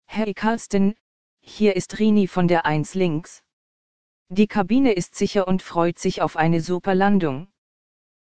CallCabinSecureLanding.ogg